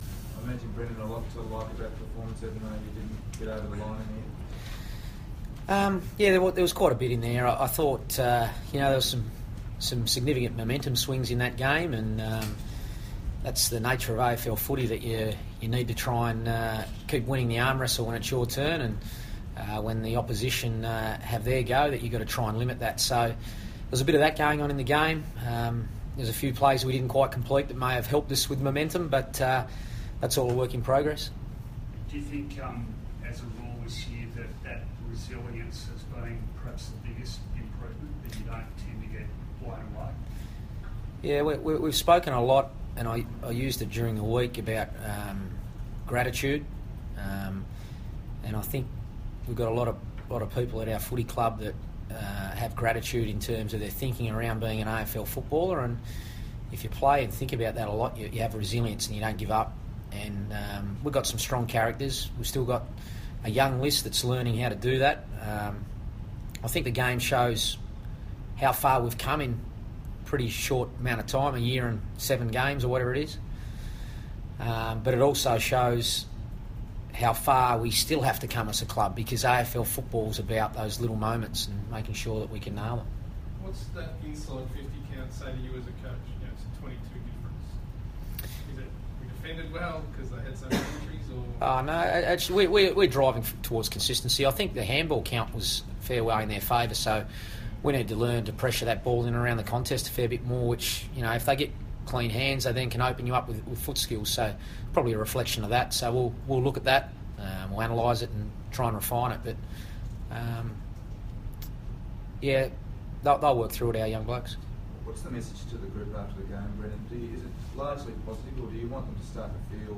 Carlton coach Brendon Bolton speaks to the media after the Blues' Round 8 loss to St Kilda.